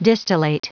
Prononciation du mot distillate en anglais (fichier audio)